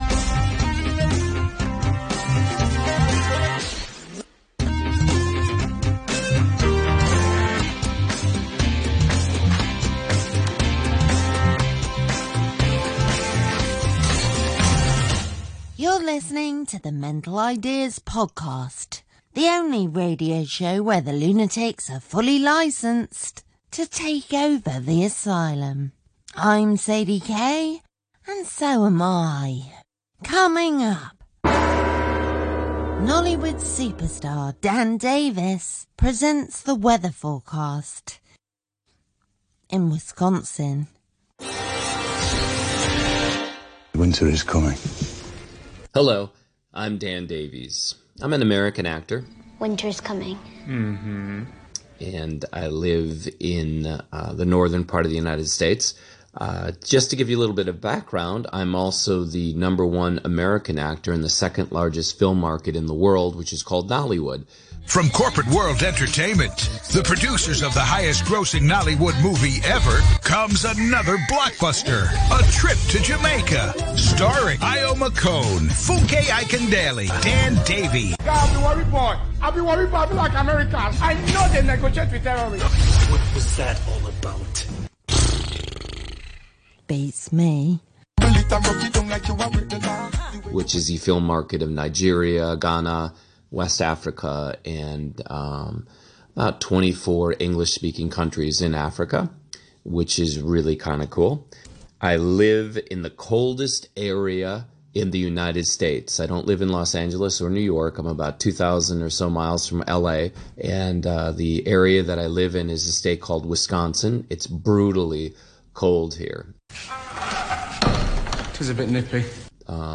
Death & The Frozen Tundra broadcasted live on RTHK Radio 3 May 14!